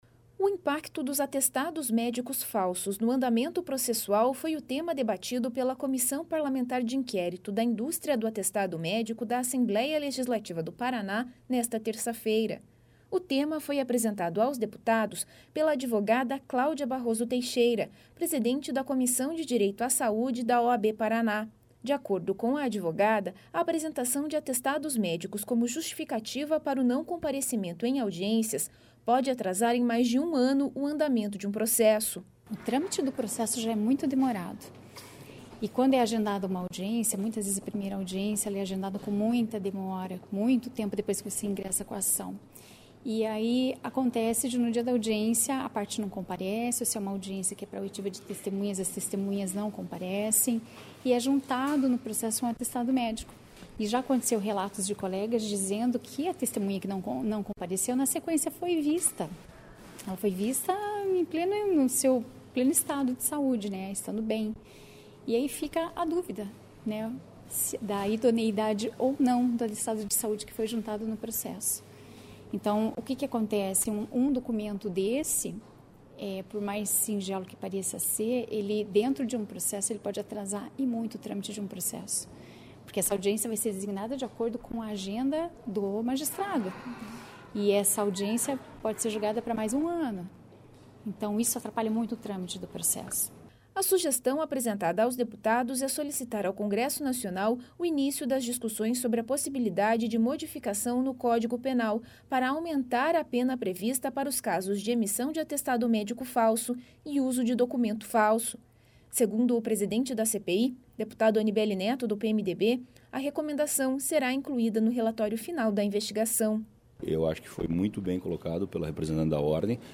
Segundo o presidente da CPI, deputado Anibelli Neto (PMDB), a recomendação será incluída no relatório final da investigação.